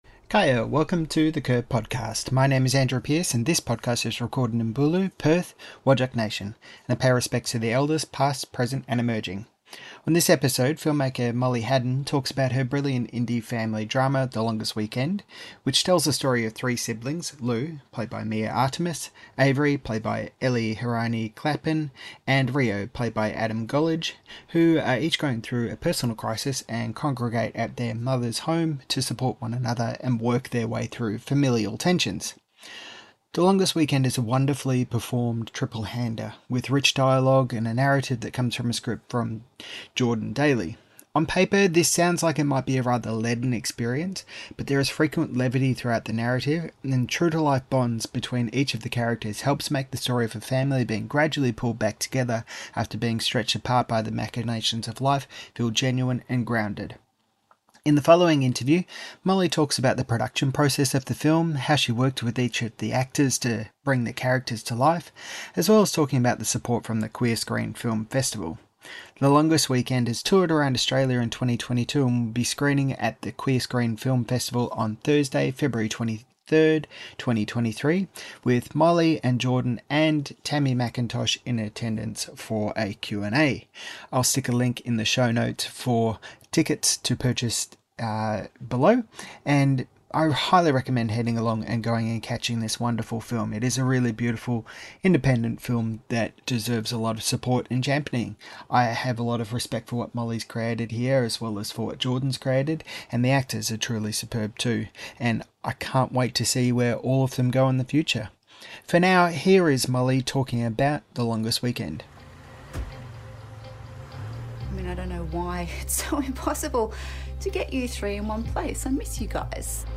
and More in This Interview - The Curb